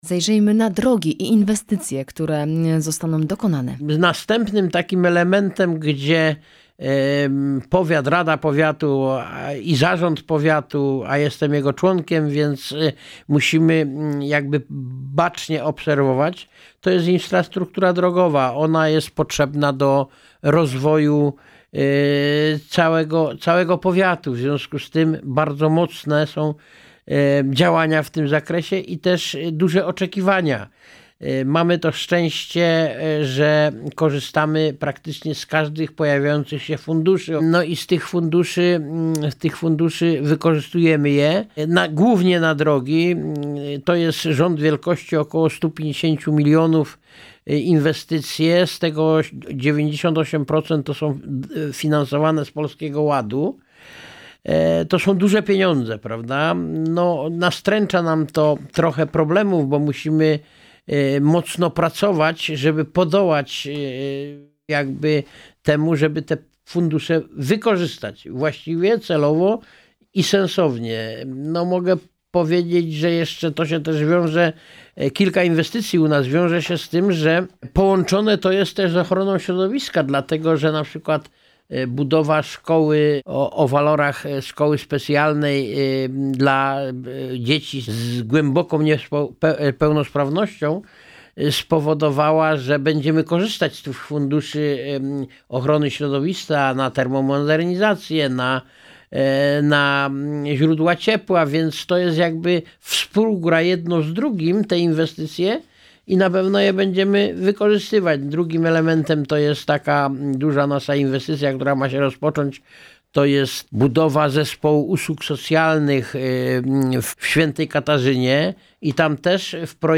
Działania z zakresu Programu Ochrony Środowiska Powiatu Wrocławskiego na lata 2024–2030, inwestycje drogowe, także III forum seniorów Powiatu Wrocławskiego – to tematy poruszane w rozmowie z Wiesławem Zającem – Członkiem Zarządu Powiatu Wrocławskiego.